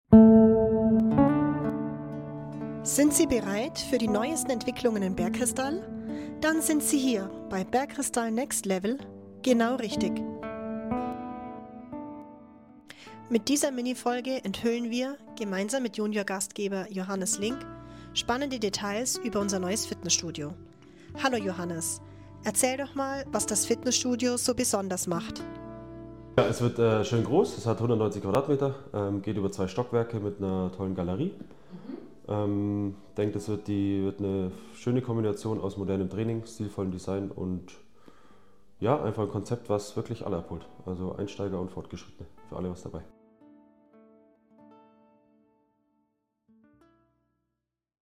Interview: Was macht unser neues Fitnessstudio so besonders?